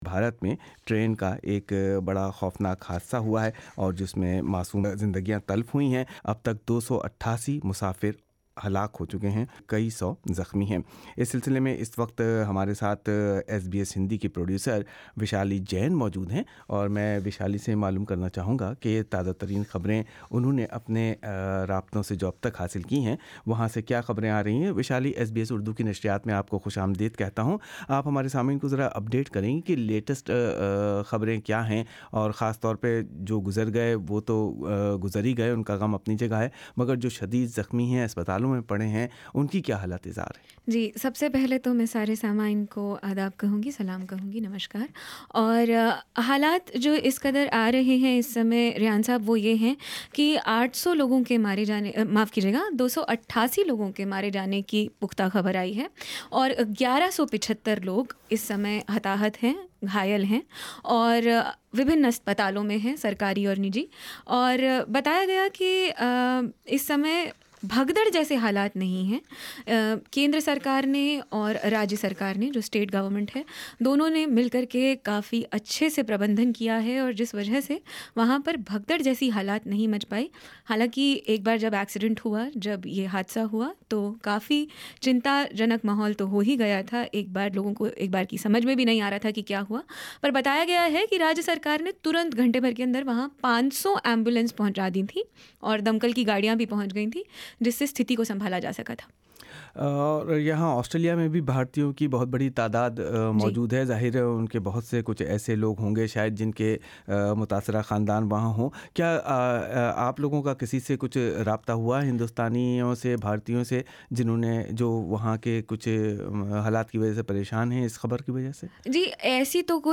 حادثے کے بارے میں کی گئی بات چیت سنئے۔